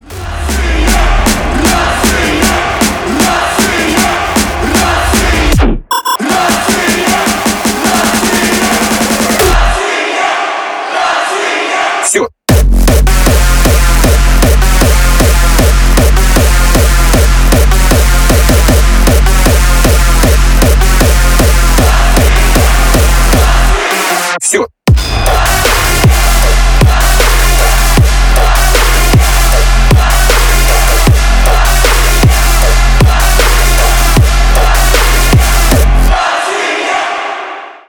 Электроника
громкие